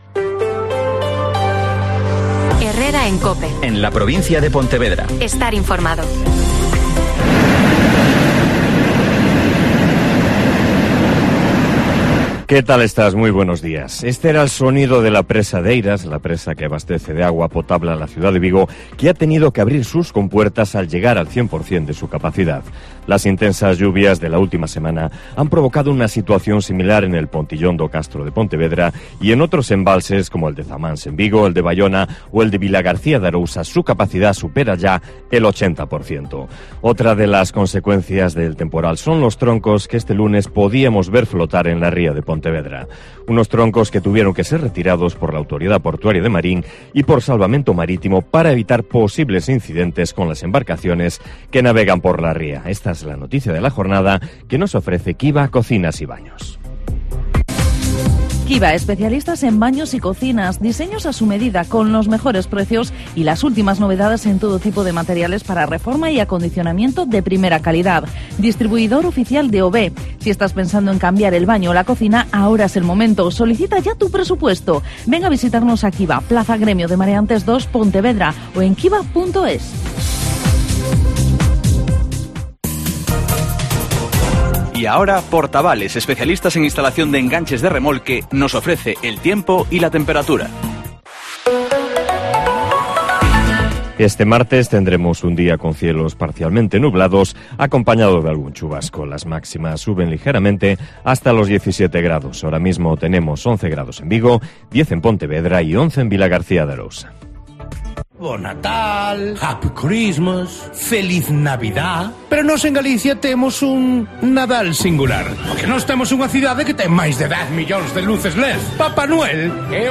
Herrera en COPE Pontevedra y COPE Ría de Arosa (Informativo 08:24h)